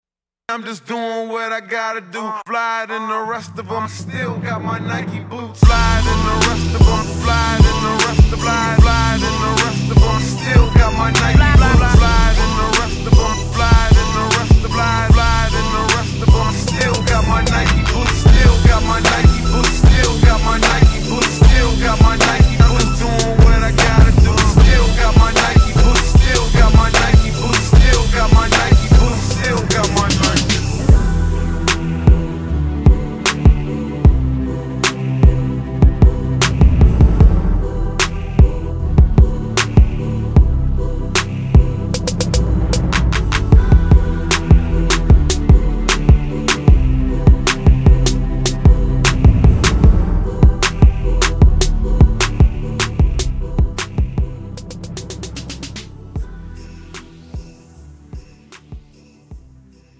(팝송) MR 반주입니다.
Premium MR은 프로 무대, 웨딩, 이벤트에 최적화된 고급 반주입니다.